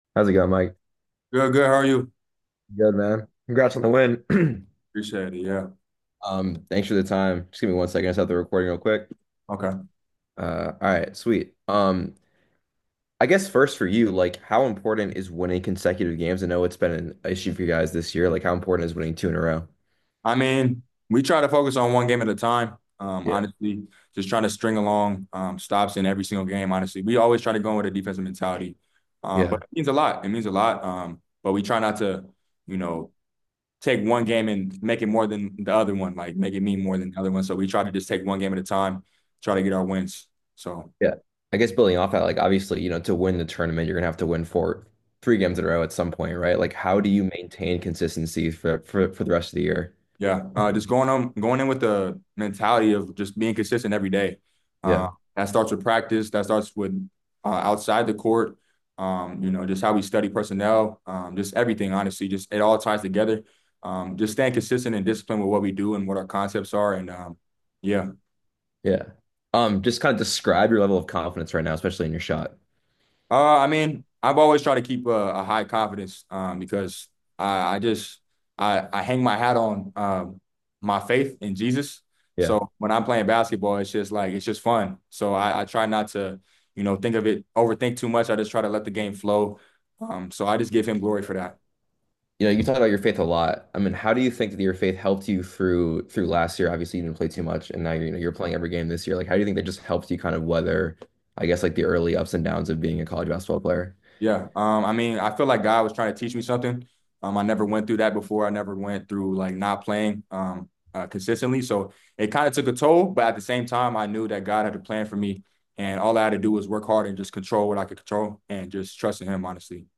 Men's Basketball / American Postgame Interview